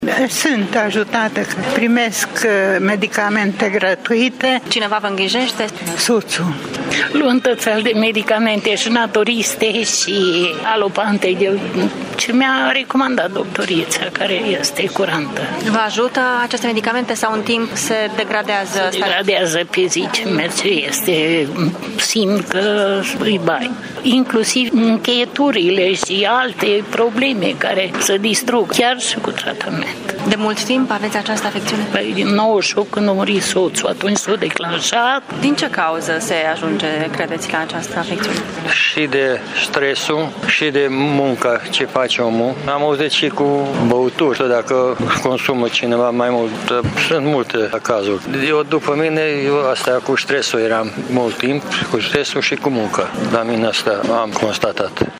Pacienții cu Parkinson spun că indiferent de tratament, evoluția bolii este continuă.